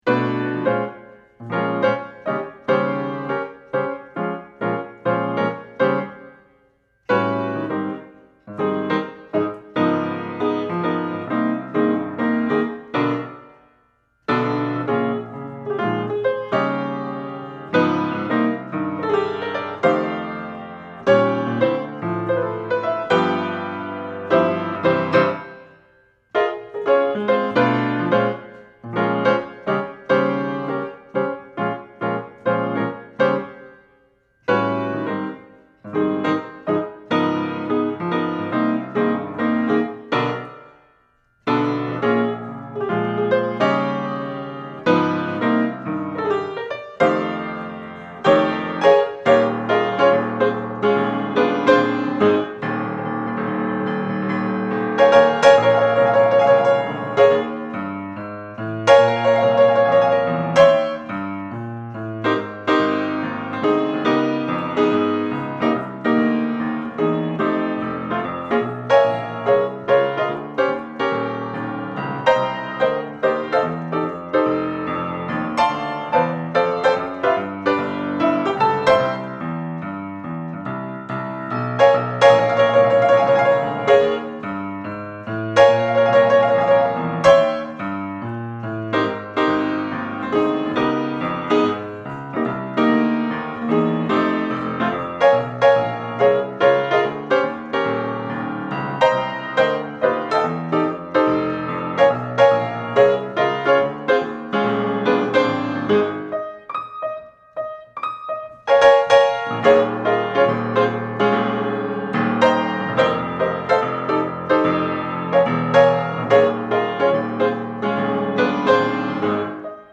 Easy Listening
Cocktail Music
Piano Jazz , Solo Piano